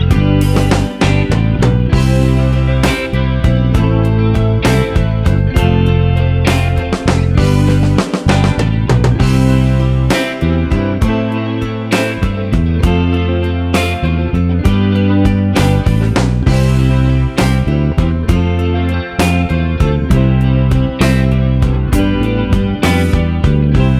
Minus Bass Glam Rock 3:34 Buy £1.50